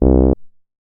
MoogResBall 012.WAV